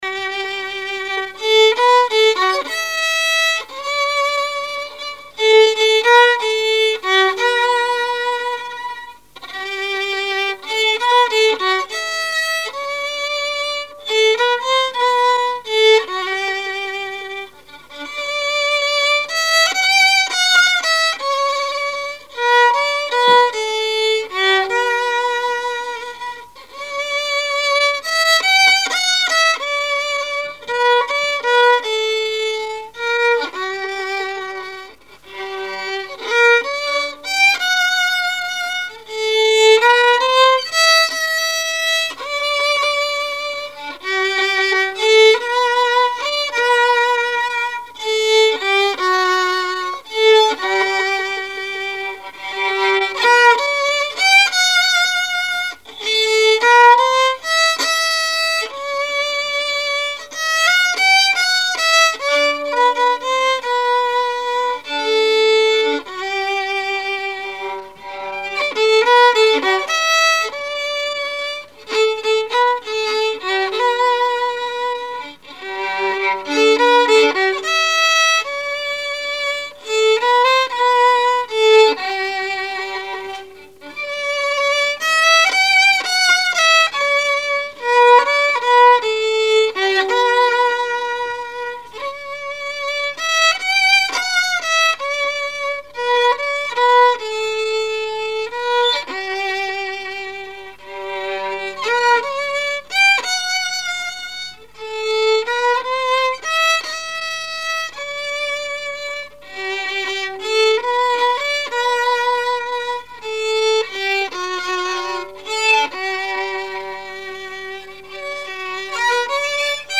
musique varieté, musichall
Genre strophique
Répertoire musical au violon
Pièce musicale inédite